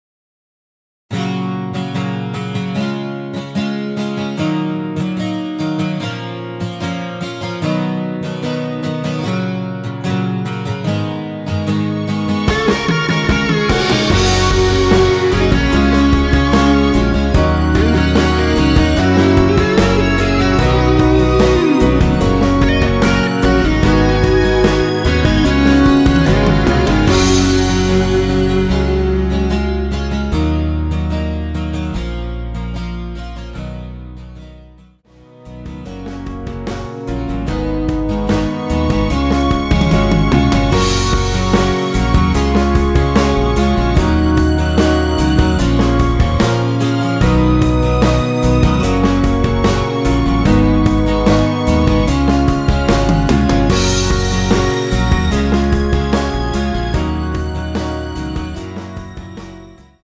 원키에서(-1)내린 MR입니다.
F#
◈ 곡명 옆 (-1)은 반음 내림, (+1)은 반음 올림 입니다.
앞부분30초, 뒷부분30초씩 편집해서 올려 드리고 있습니다.